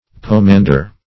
Pomander \Po*man"der\, n. [Sp. poma.]